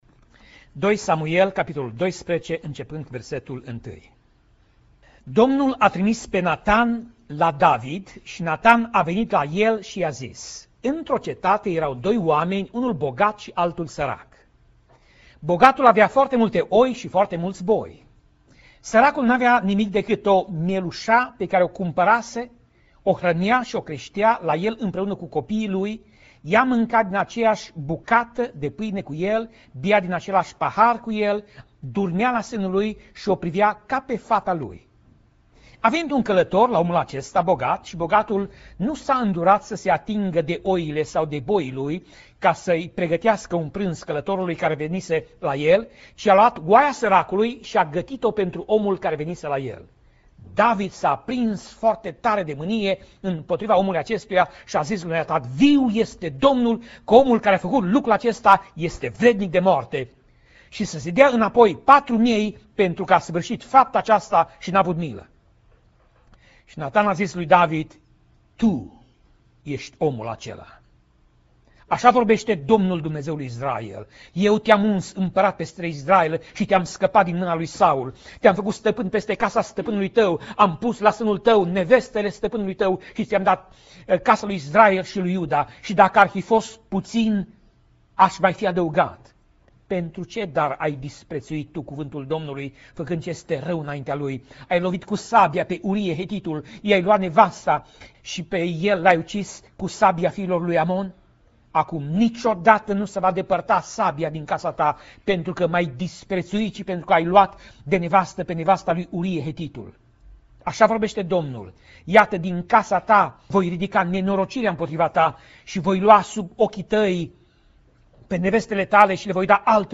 Pasaj Biblie: 2 Samuel 12:1 - 2 Samuel 12:15 Tip Mesaj: Predica